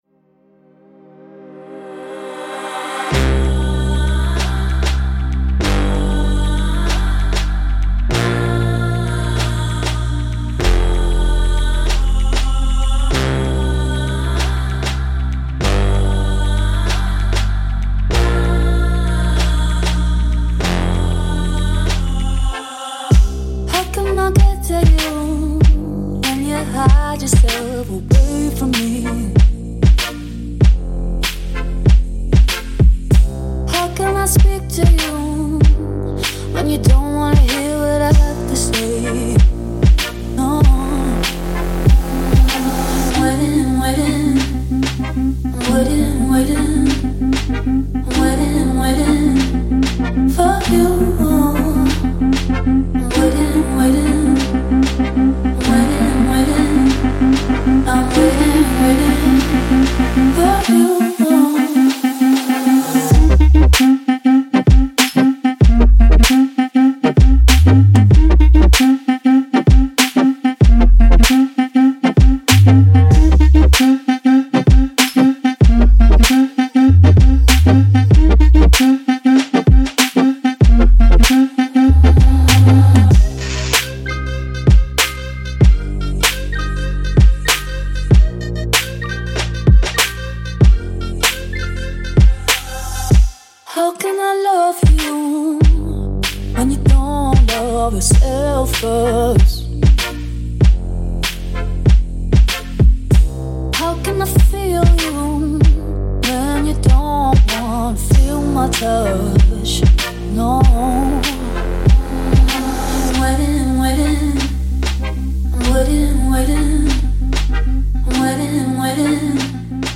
# future bass